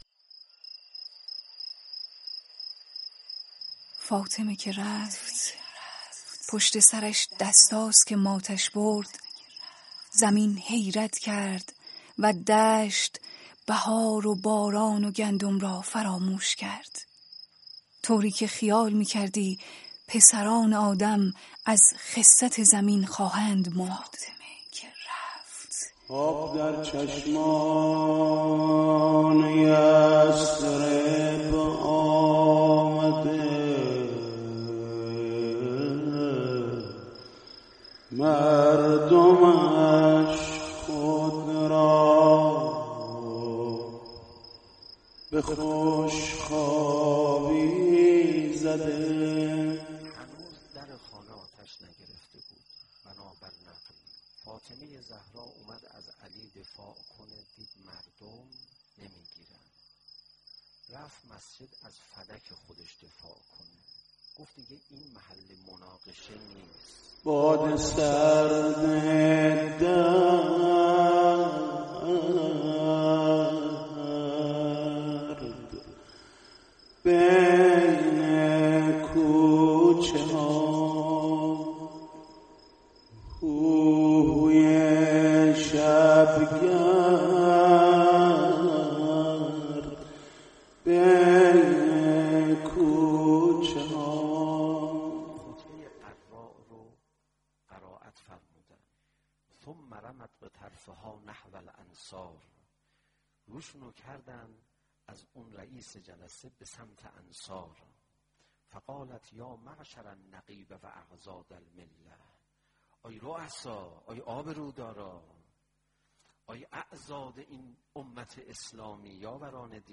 صوت/ دعای رهبر انقلاب پای سفره مادر
بعد از پایان مراسم عزاداری ایام شهادت حضرت فاطمه‌الزهرا سلام‌الله‌علیها در حسینیه‌ی امام خمینی حضرت آیت‌الله خامنه‌ای در جمع دست‌اندرکاران اقامه عزای فاطمی دست به دعا برداشتند.